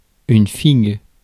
Ääntäminen
Synonyymit vulve Ääntäminen France: IPA: [fiɡ] Haettu sana löytyi näillä lähdekielillä: ranska Käännös Substantiivit 1. viigimari Suku: f .